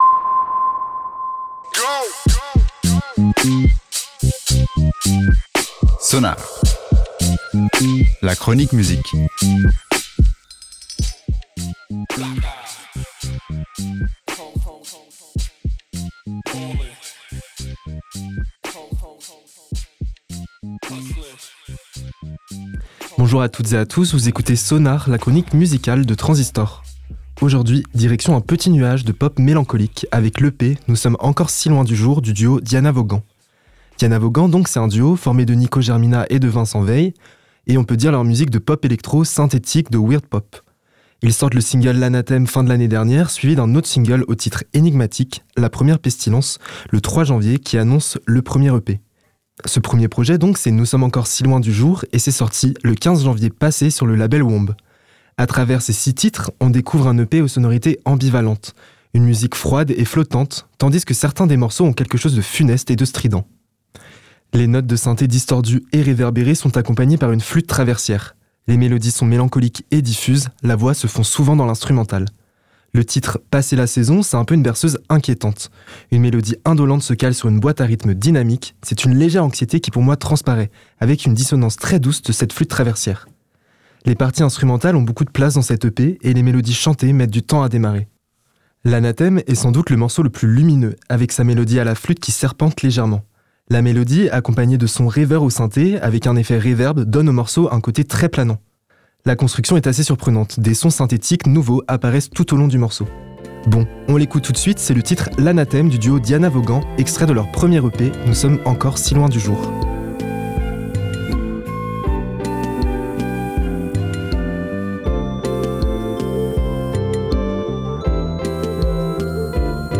la pop mélancolique